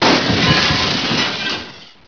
Glassbreak2.wav